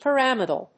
発音記号
• / pərˈæmədl(米国英語)